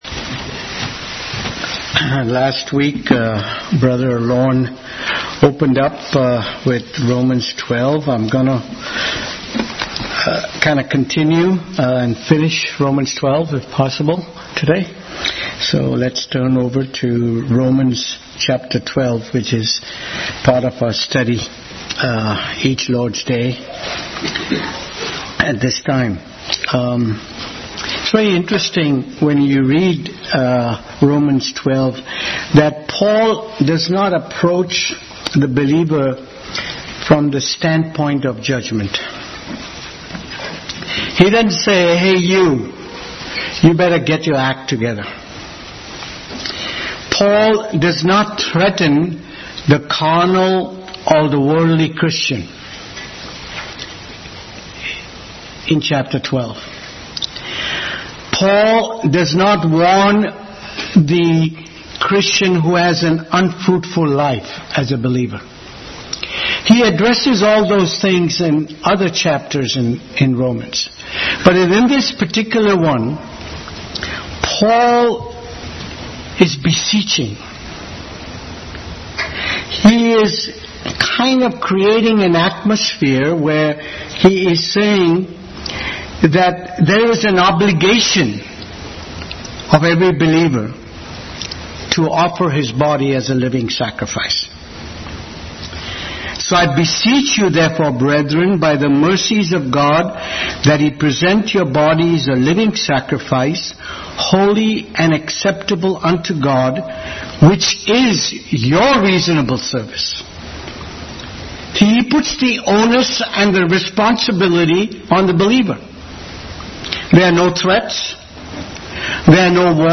Bible Text: Romans 12:1-21, Exodus 29:36-37, 1 Corinthians 5:7-8, 2 Timothy 2:20-22, Ephesians 4:22-32, 2 Corinthians 7:4 | Adult Sunday School. Continued study in the book of Romans.